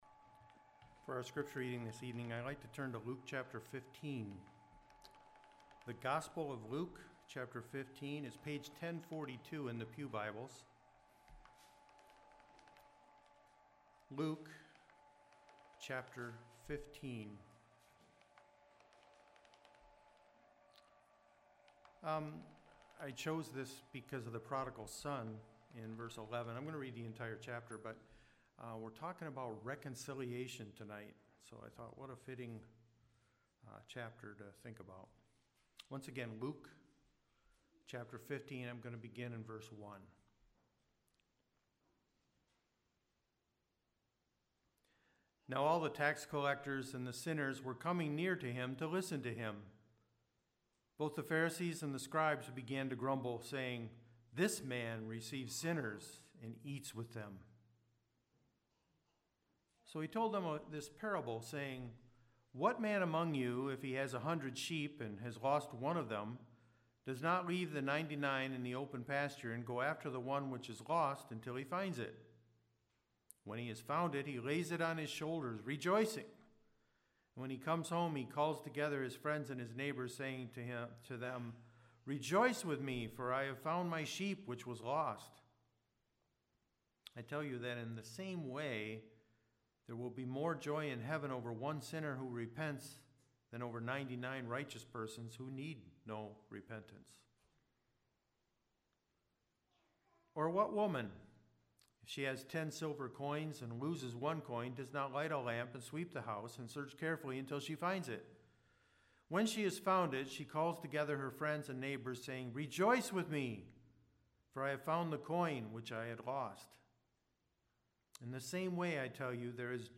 The greatest witness | SermonAudio Broadcaster is Live View the Live Stream Share this sermon Disabled by adblocker Copy URL Copied!